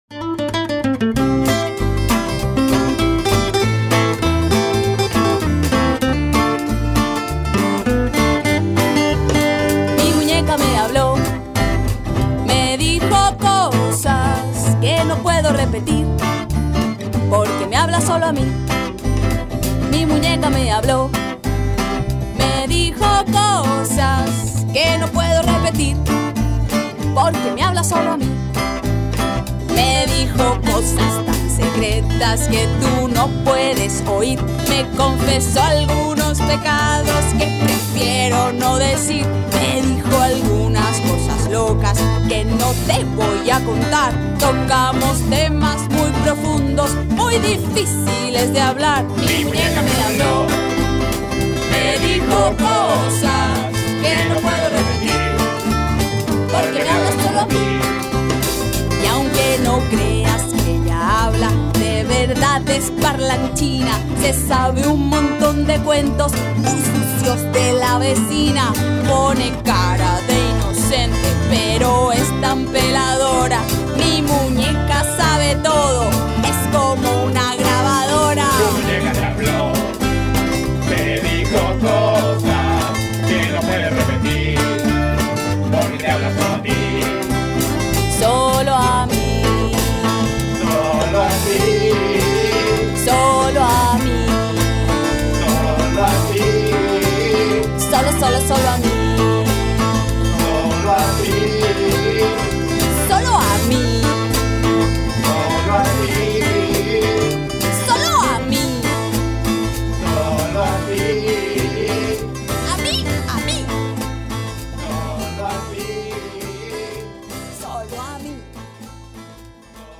Canción